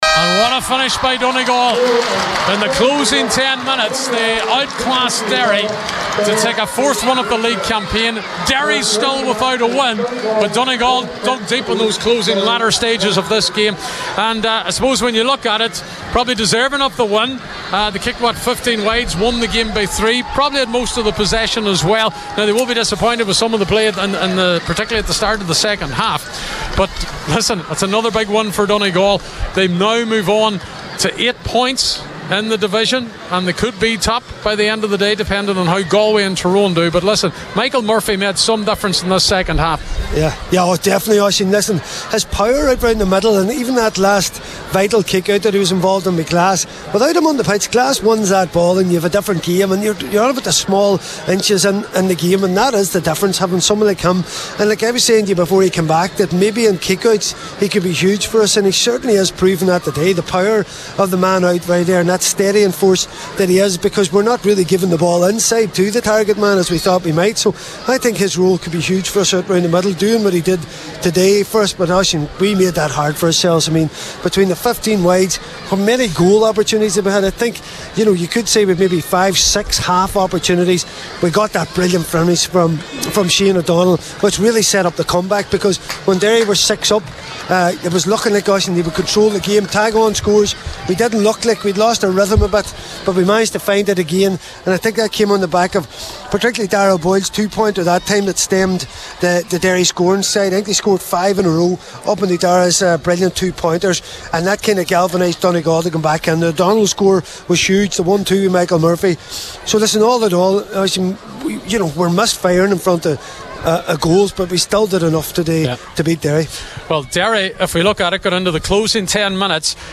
were live at full time for Highland Radio Sunday Sport…